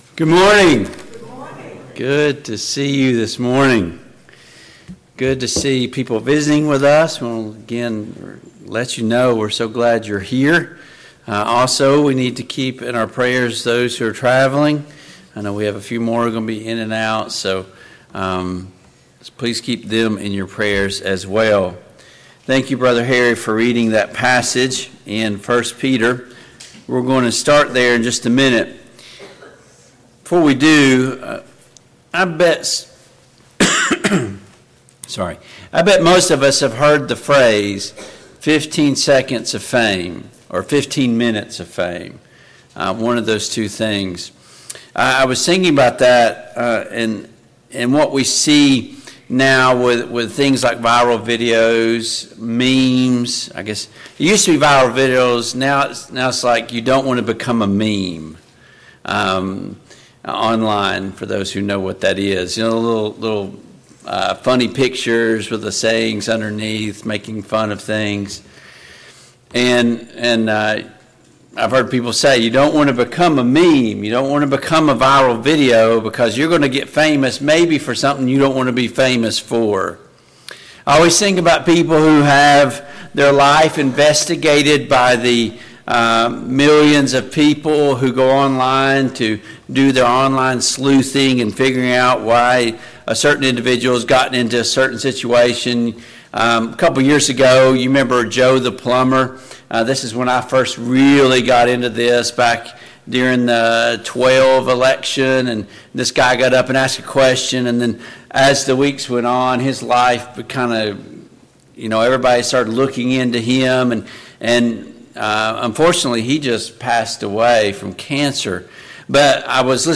Service Type: AM Worship Topics: The Apostle Peter